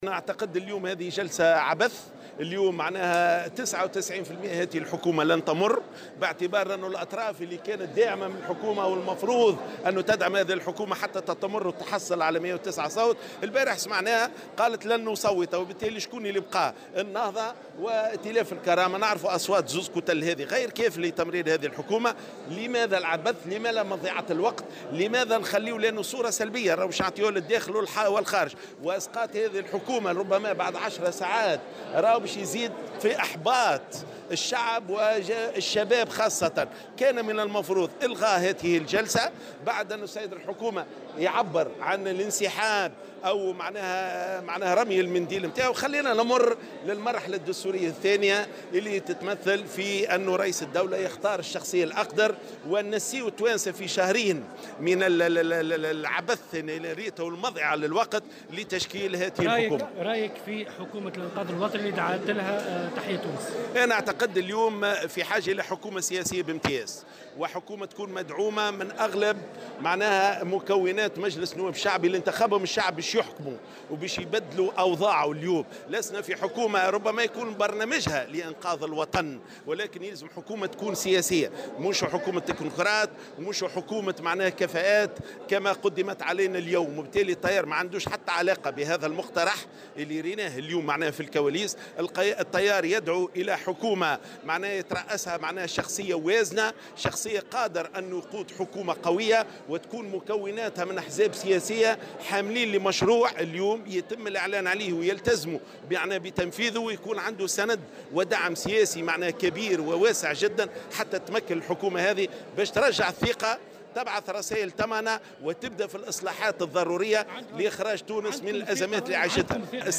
وأضاف في تصريح لمراسل "الجوهرة أف أم" أنه كان من المفروض إلغاء الجلسة العامة والمرور إلى المرحلة الدستورية الثانية والتي يقوم بمقتضاها رئيس الجمهورية باختيار الشخصية الأقدر.